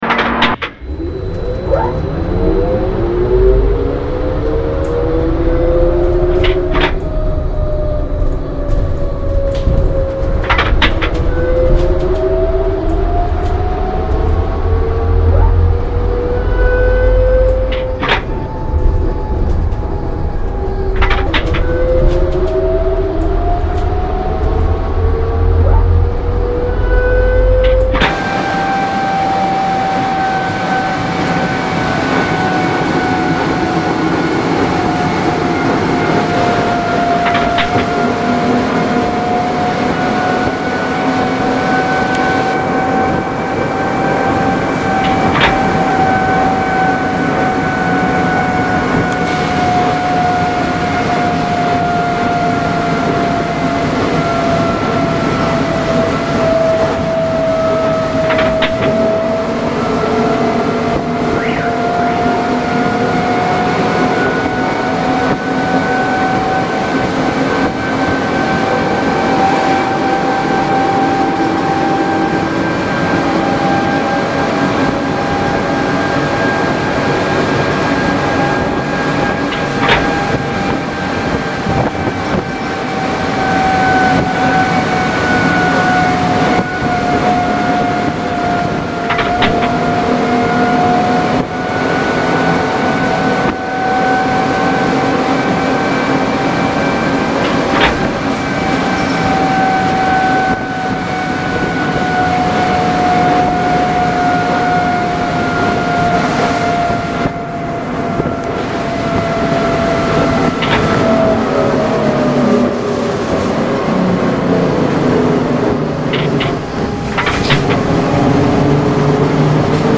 Звук разгона троллейбуса на первой скорости